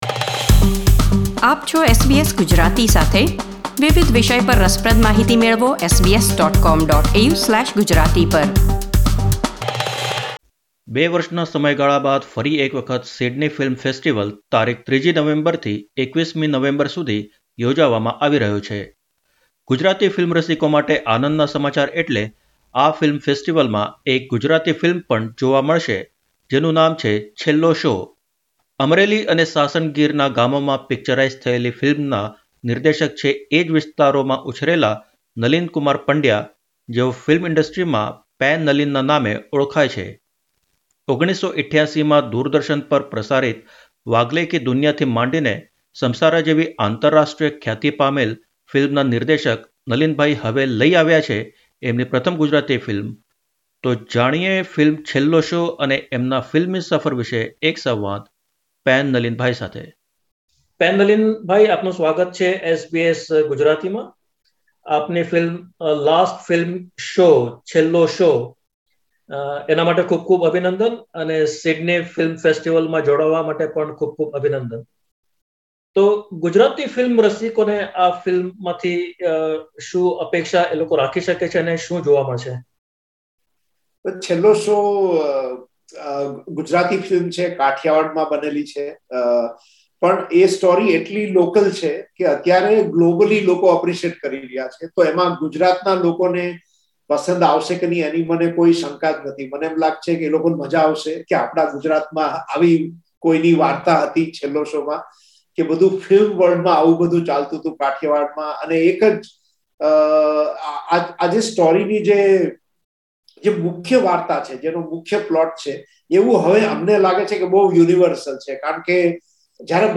વિશ્વના વિવિધ દેશો અને ભાષાની ફિલ્મોમાં ગુજરાતી ફિલ્મ 'છેલ્લો શો' (Last Film Show) ને પણ સ્થાન મળ્યું છે. આ ફિલ્મ 5મી તથા 12મી નવેમ્બરના રોજ પ્રદર્શિત થશે. 9 વર્ષીય બાળકે પ્રથમ વખત ફિલ્મ જોઇ પછી તેના જીવનમાં શું પરિવર્તન આવ્યું તેના પર બનેલી ફિલ્મ વિષે દિગ્દર્શક નલિનકુમાર પંડ્યાએ SBS Gujarati સાથે વાત કરી.